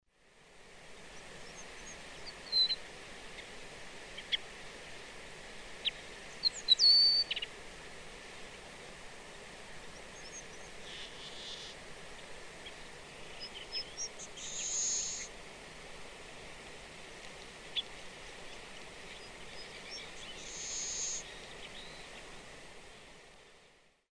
Sturnella defilippii - Loica pampeana
Sturnella defilippii.mp3